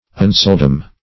unseldom - definition of unseldom - synonyms, pronunciation, spelling from Free Dictionary Search Result for " unseldom" : The Collaborative International Dictionary of English v.0.48: Unseldom \Un*sel"dom\, adv.